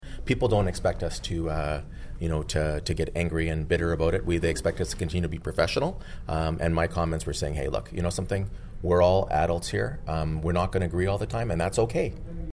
As the council meeting wrapped up, Mayor Panciuk spoke about the tenor of debate in council, and how he felt it should be more collaborative.